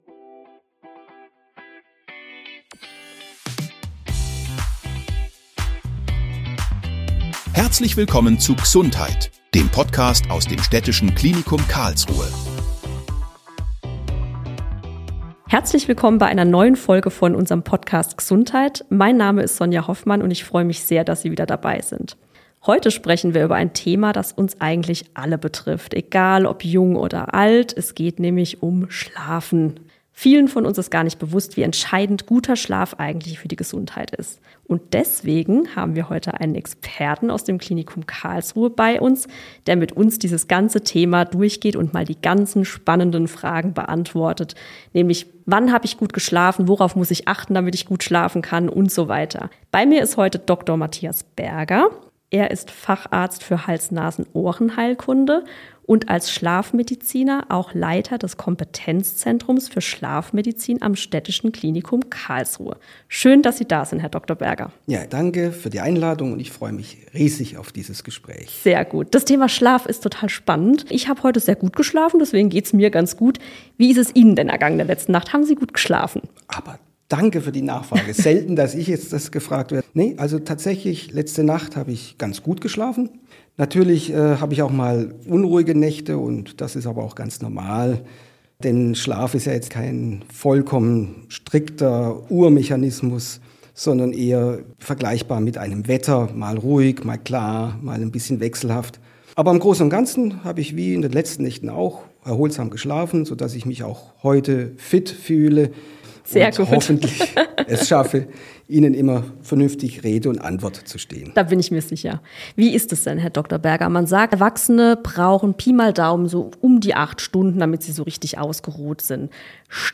Die Folge darf natürlich auch zum Einschlafen gehört werden.